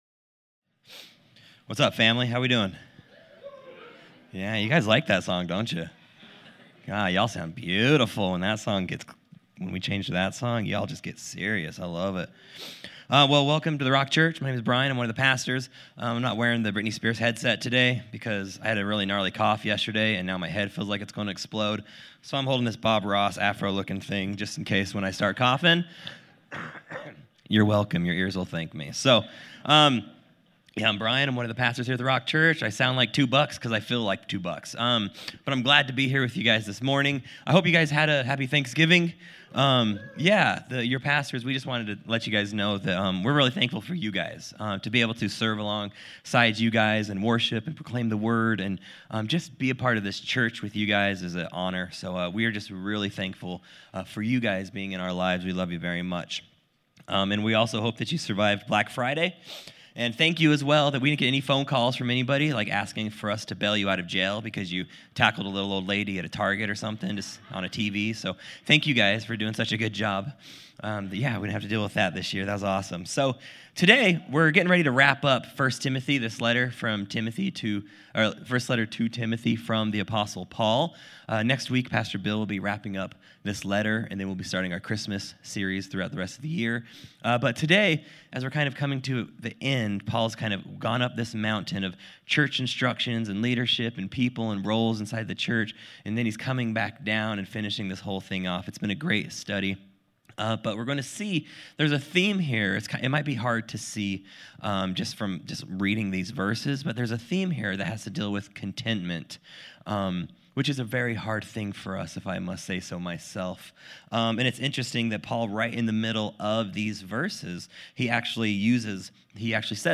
Get Wisdom: Practical Wisdom for Life from the Book of Proverbs, is a 14-week sermon series from The Rock Church in Draper Utah.